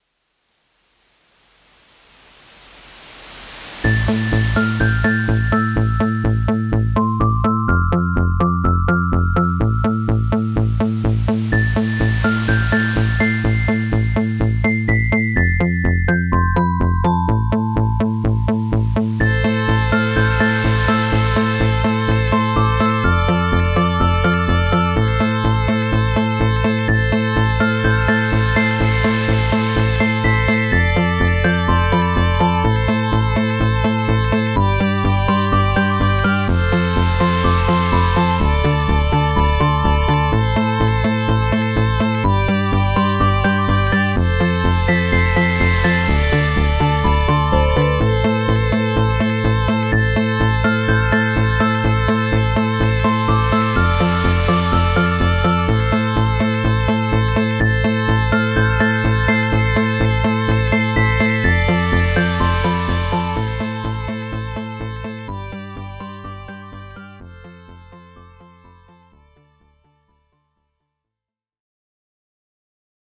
Vikkelä musiikki